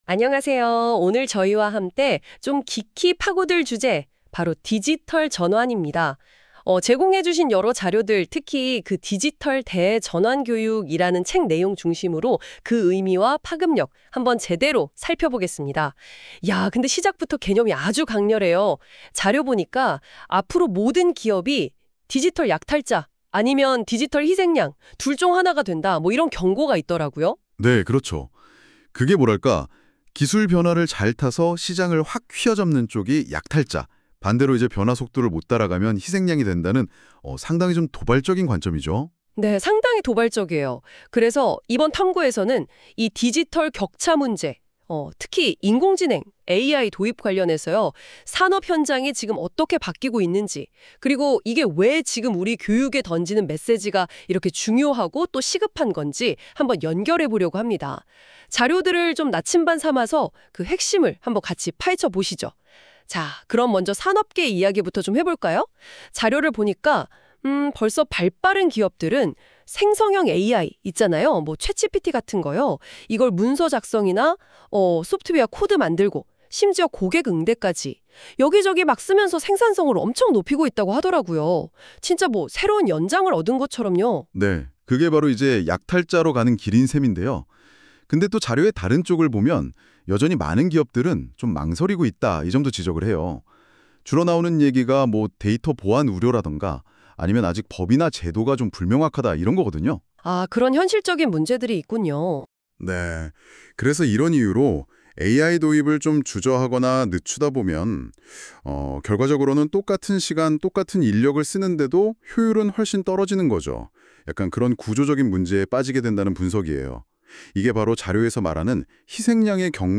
10화 AI가 만든 음성, AI가 보정!